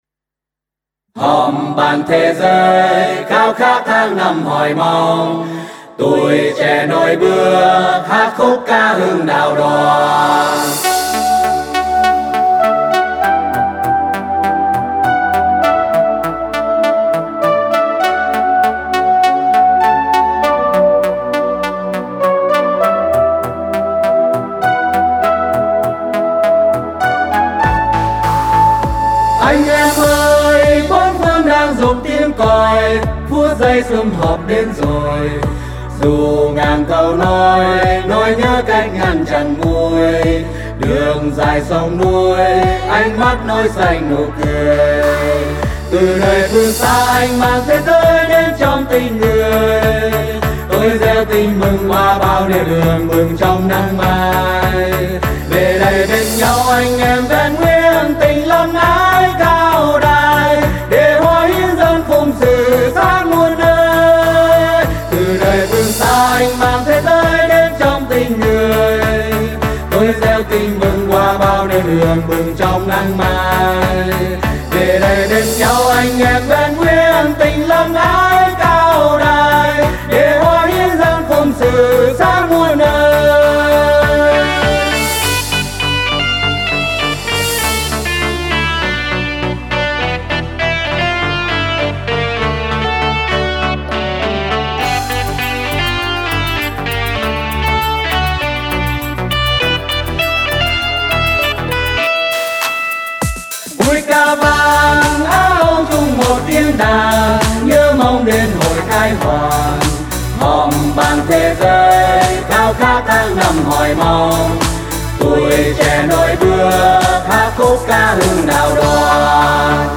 •   Vocal  04.